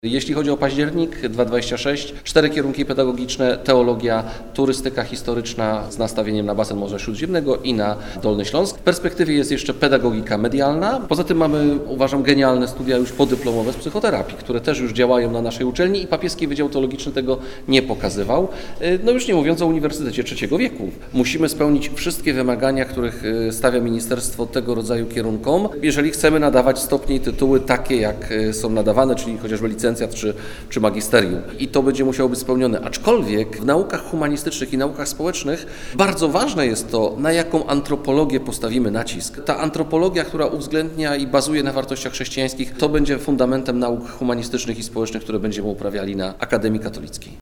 Briefing ogłaszający powstanie Akademii Katolickiej we Wrocławiu, 27.01.2026